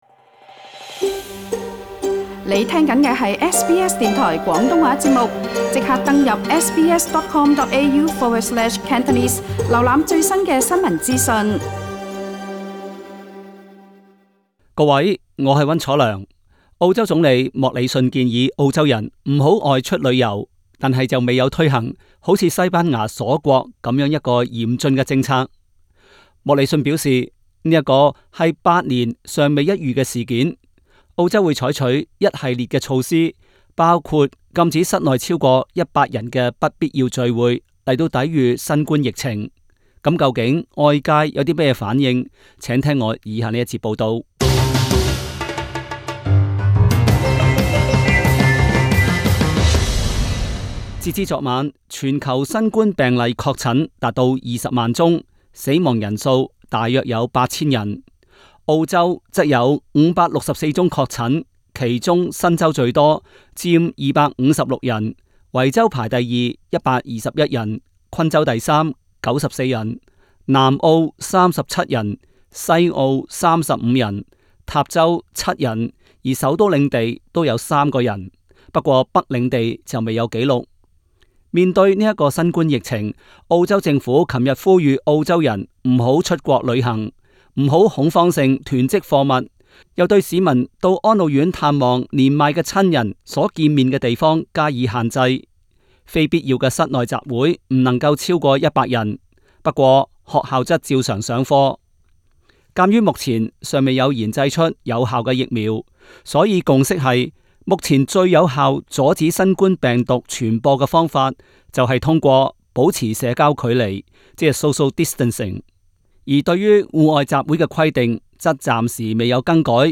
Source: AAP SBS廣東話節目 View Podcast Series Follow and Subscribe Apple Podcasts YouTube Spotify Download (11.68MB) Download the SBS Audio app Available on iOS and Android 新冠疫情全球肆虐，不同國家採取了不同的抗疫措施，有些比較寬鬆，有些則比較嚴厲。